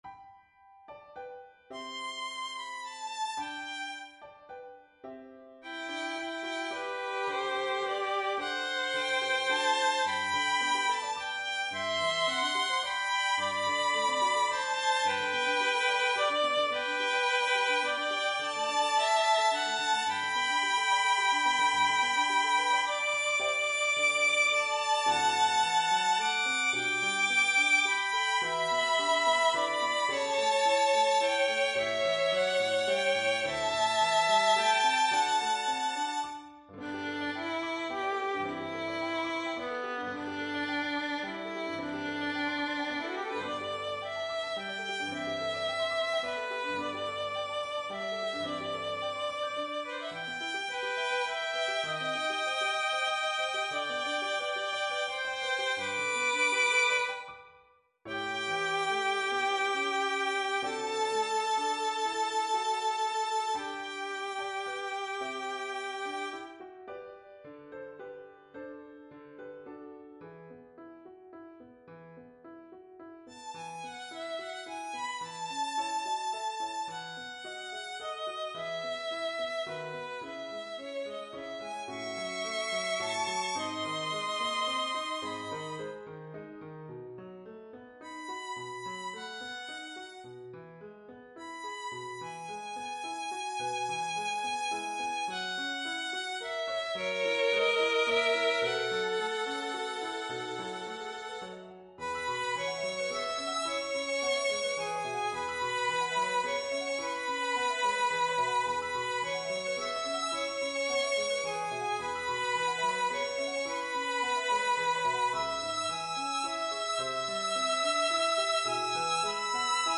G, E
Violin and Piano